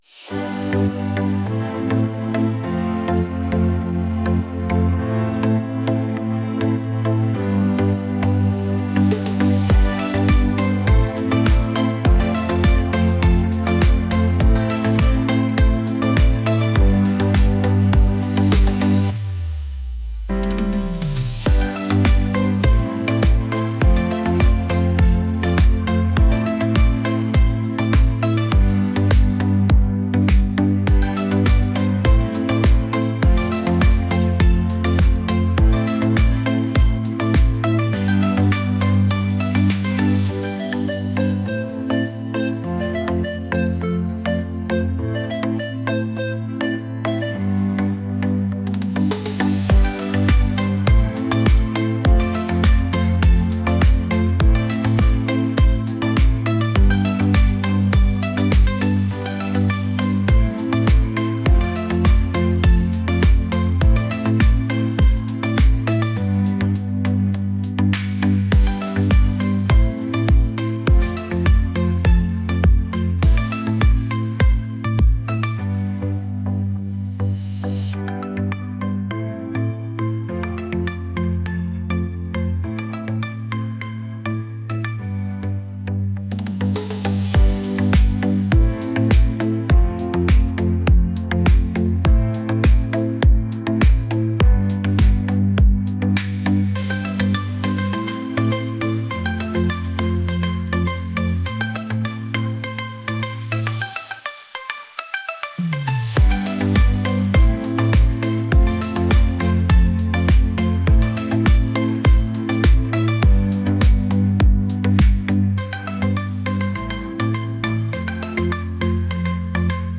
pop | upbeat